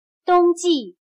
冬季/dōngjì/invierno